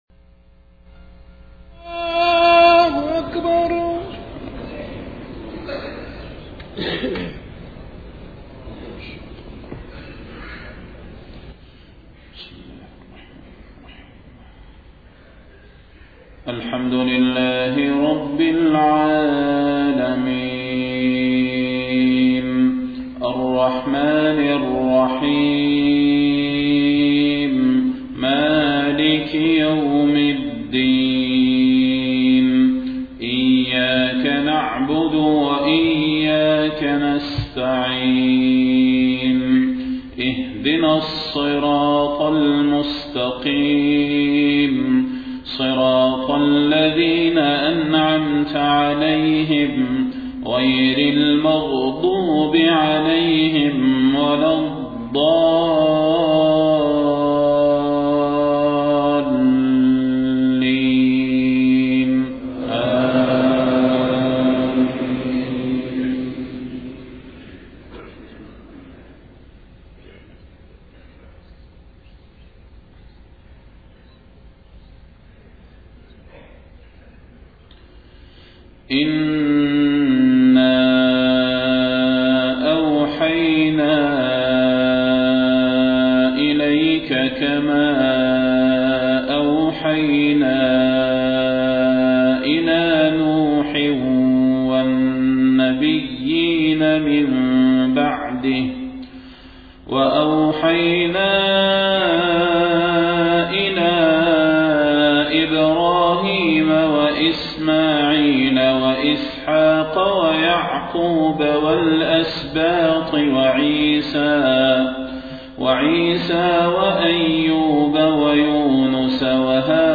صلاة الفجر 26 صفر 1431هـ من سورة النساء 163-175 > 1431 🕌 > الفروض - تلاوات الحرمين